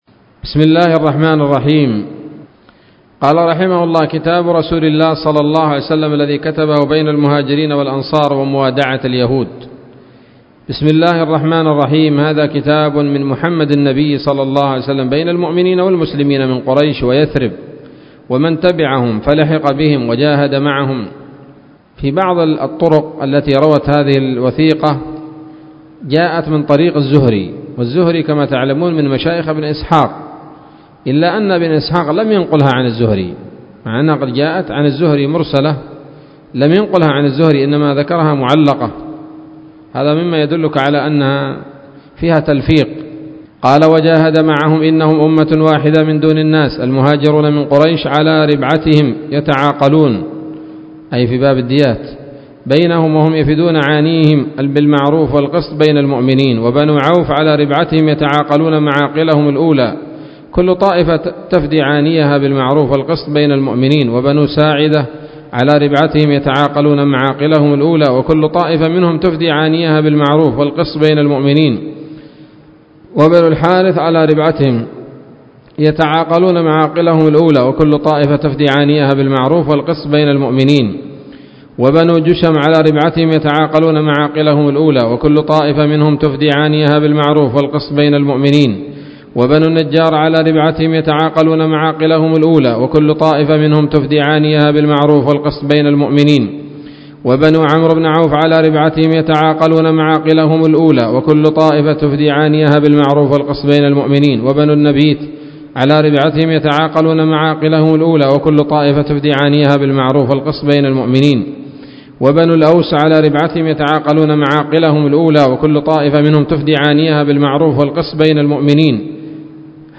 الدرس الثامن والسبعون من التعليق على كتاب السيرة النبوية لابن هشام